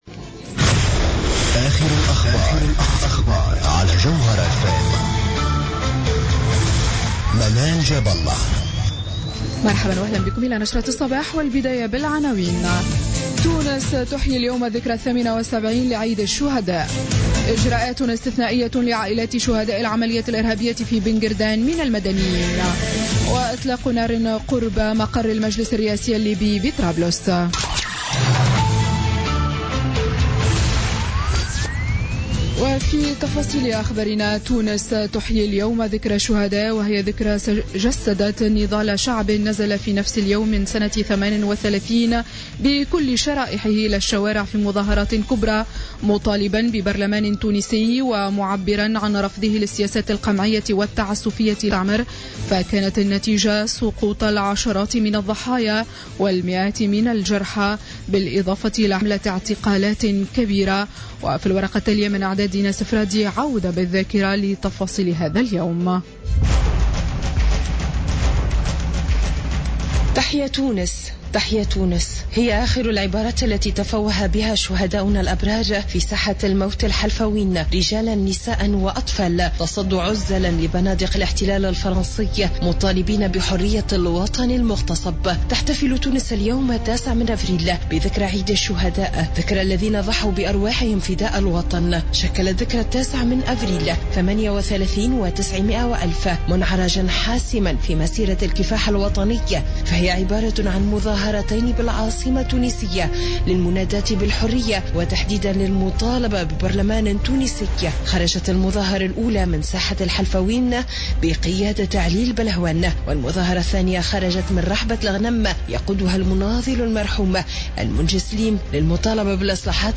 Journal Info 07h00 du samedi 09 Avril 2016